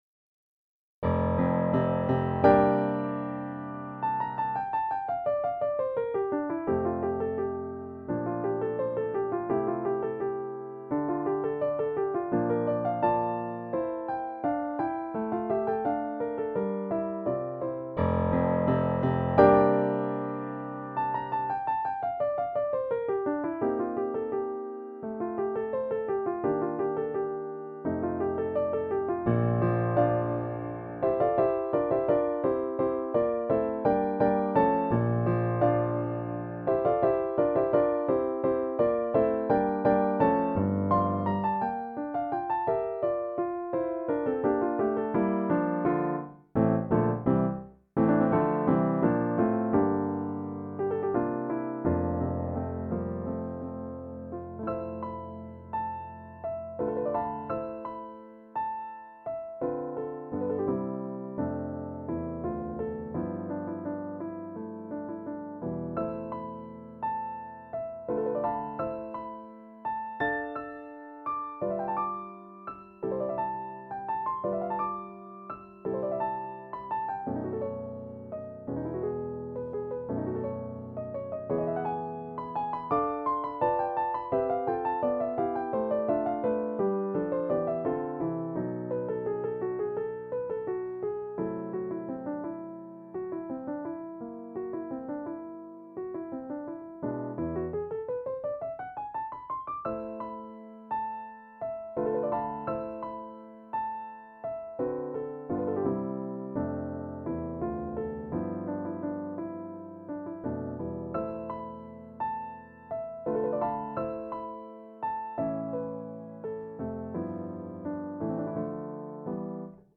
Der Ant. Petrof Grand Piano 275 Flügel hat einen eigenständigen Klang und bietet selbst in Form der MP3 Dateien einen Eindruck davon, wie er einen Raum füllen kann.